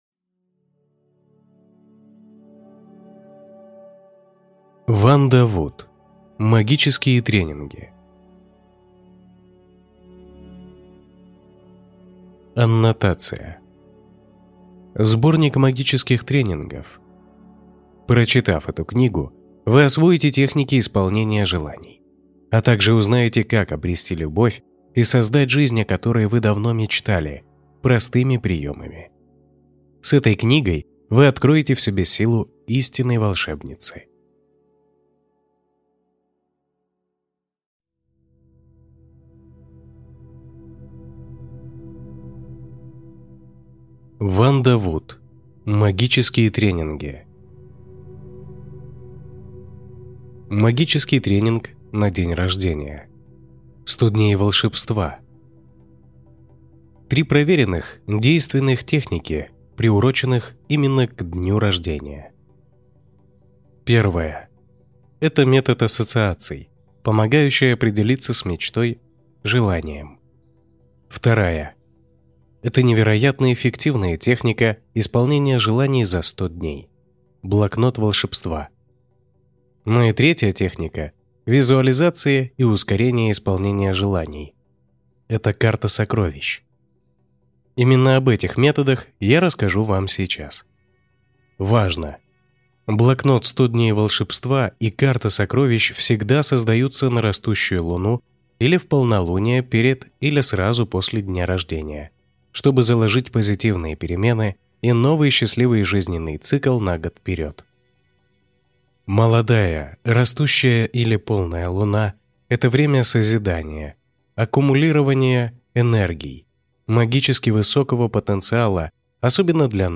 Аудиокнига Магические тренинги | Библиотека аудиокниг
Прослушать и бесплатно скачать фрагмент аудиокниги